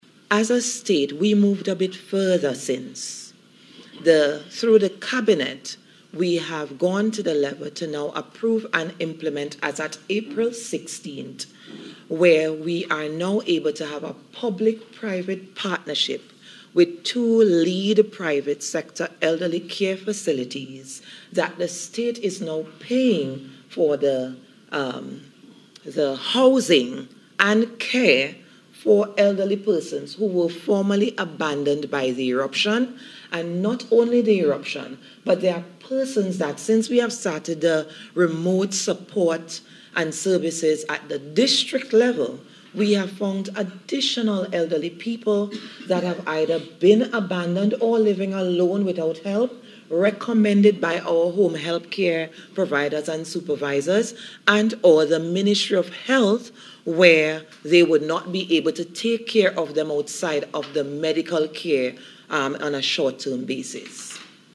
Meanwhile, Permanent Secretary in the Ministry of National Mobilization, Marissa Finch-Burke, spoke of the steps taken by the Government to safeguard the welfare of elderly persons who were abandoned during the eruption of La Soufriere Volcano.